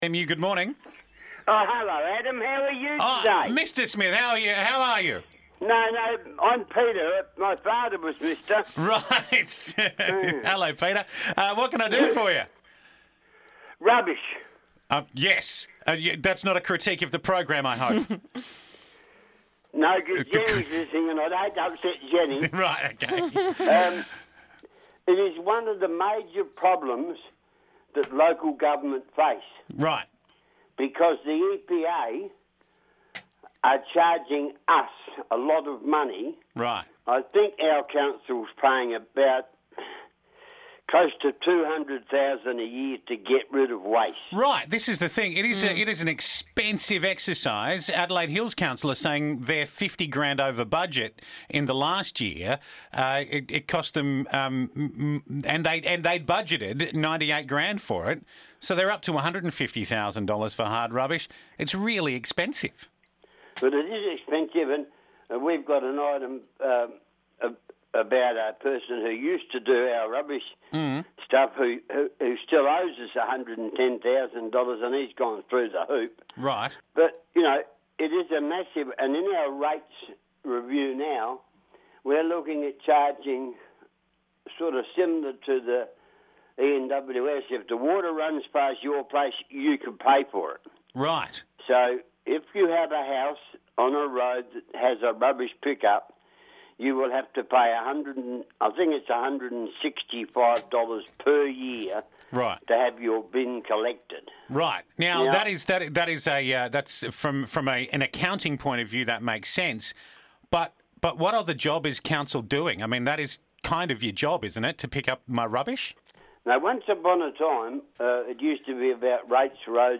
Councillor Call In To Discuss User Pays Rubbish Collection